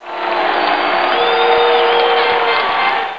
/ MacWorld 1998 February / Macworld (1998-02).dmg / Games World / Hot New Demos / Football'97DemoMac / SOUNDS / CHEERS / BOOS.WAV
BOOS.WAV